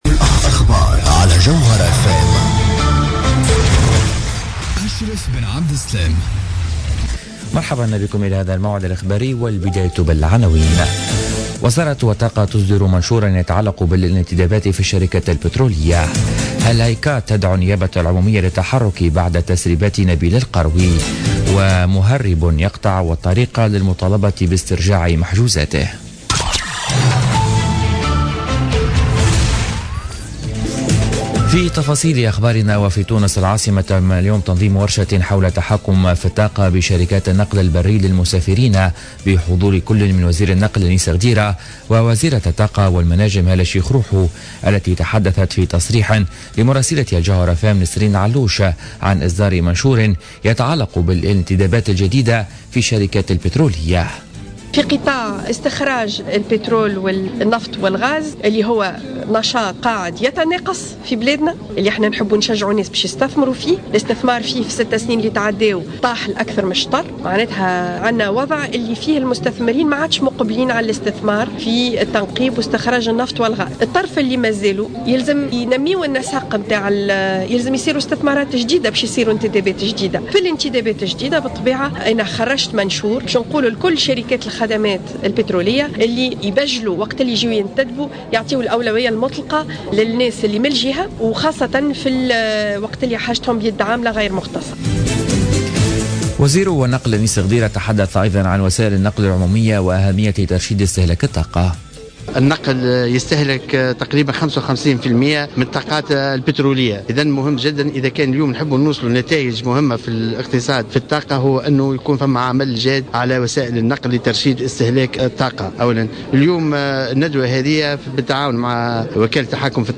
نشرة أخبار منتصف النهار ليوم الثلاثاء 18 أفريل 2017